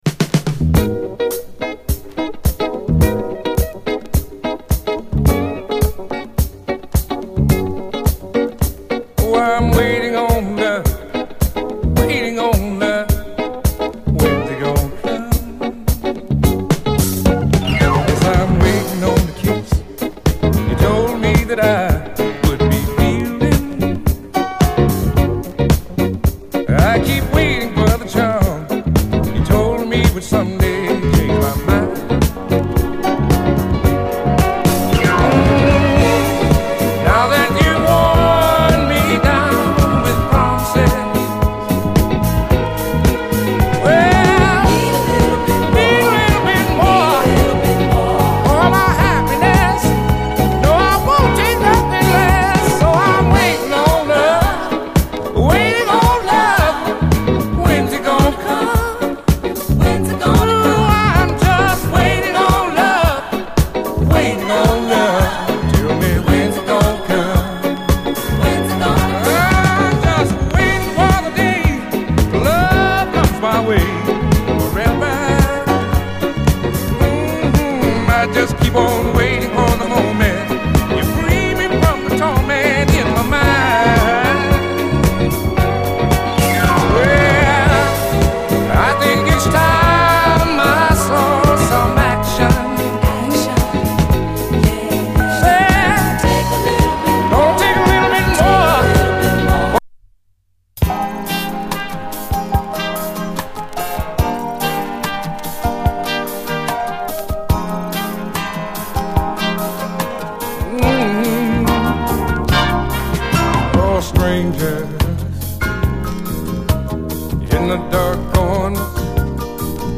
SOUL, 70's～ SOUL
軽快なモダン・ダンサー